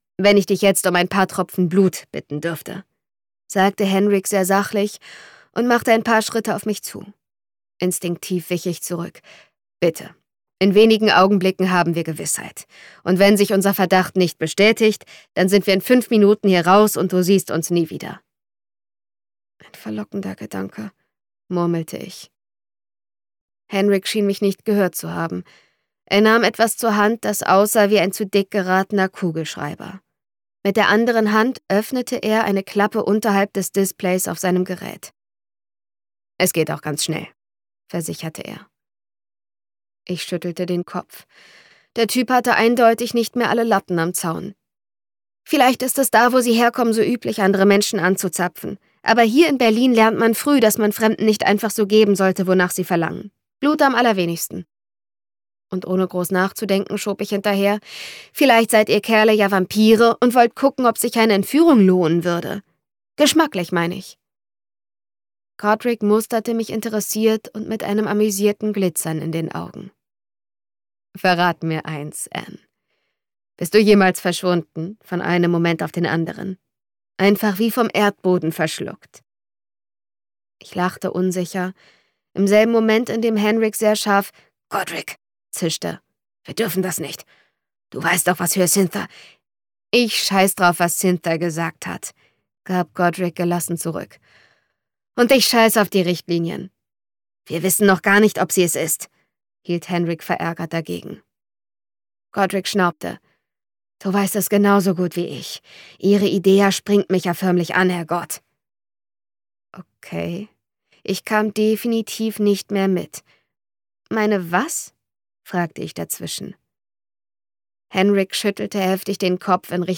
Super gesprochen.